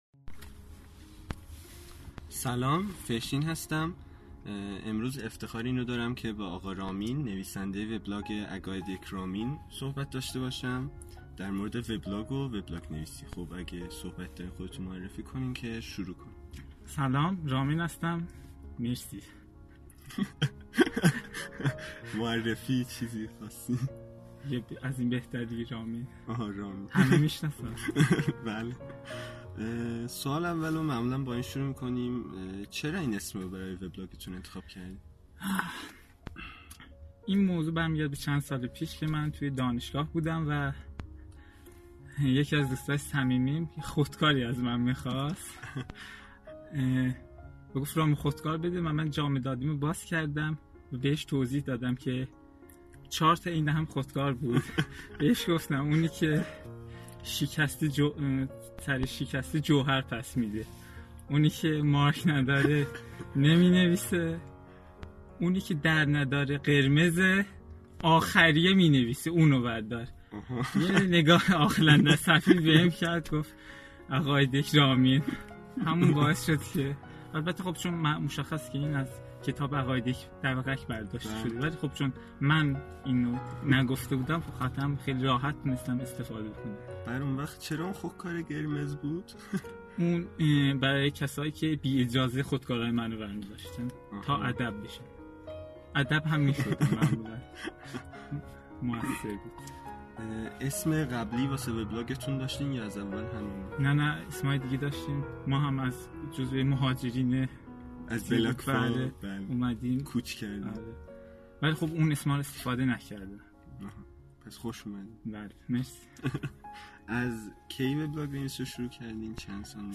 مصاحبه با بلاگرها 5